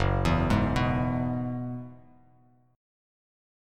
Gb9sus4 chord